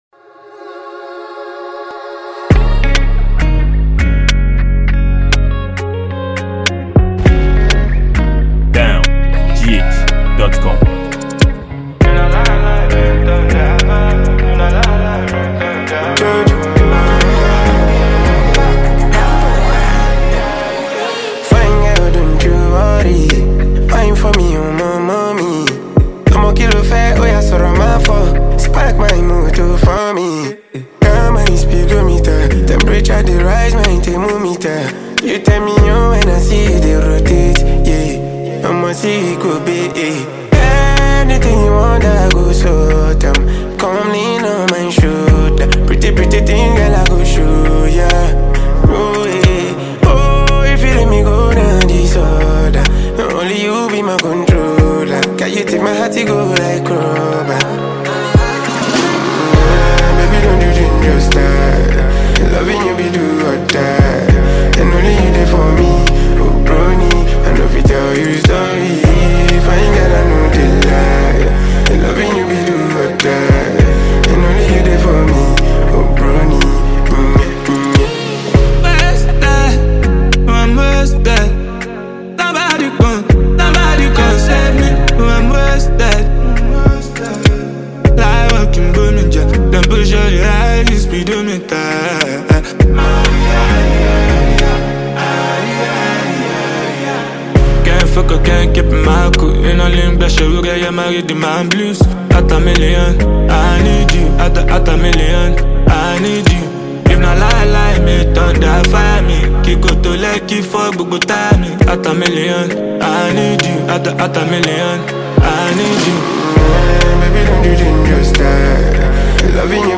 Highlife and afrobeat singer